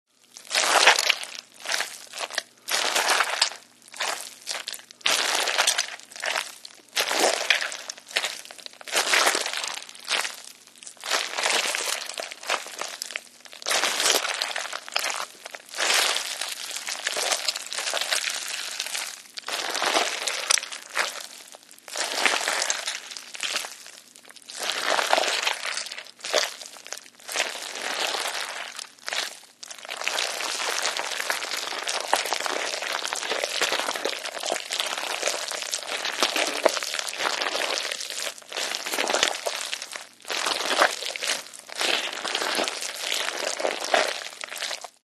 Здесь собраны разнообразные аудиоэффекты: от мягкого постукивания по кожуре до сочного хруста при разрезании.
Звук извлечения внутренностей тыквы